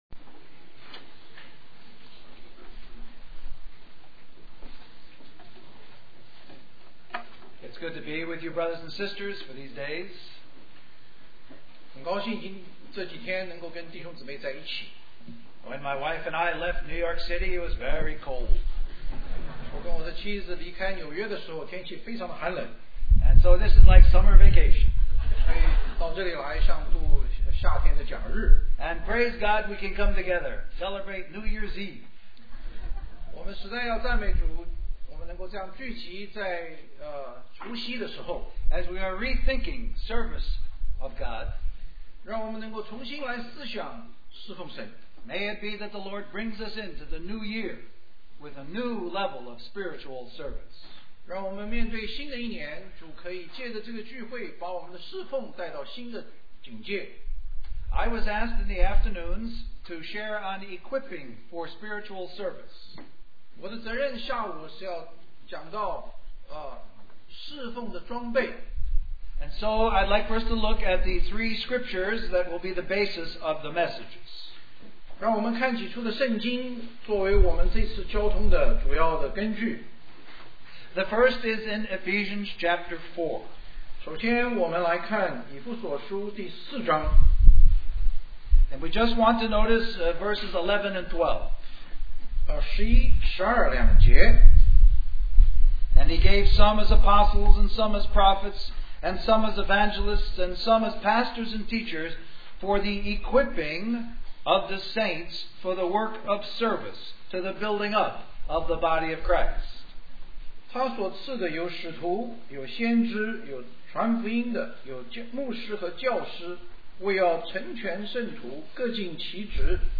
Special Conference For Service, Australia
Message